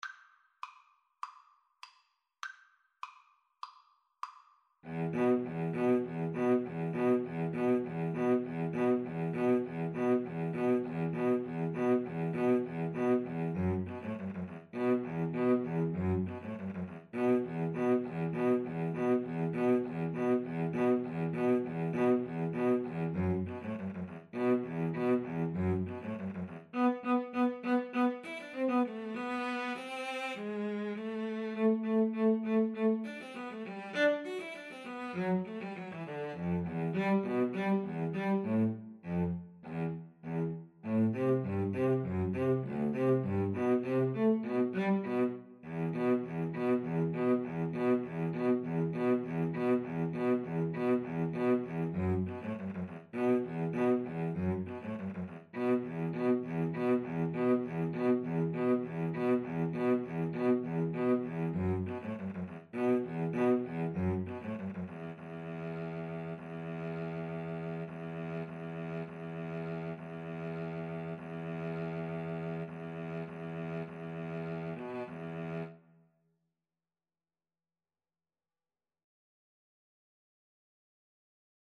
E minor (Sounding Pitch) (View more E minor Music for Cello Duet )
Allegro Moderato (View more music marked Allegro)
Cello Duet  (View more Easy Cello Duet Music)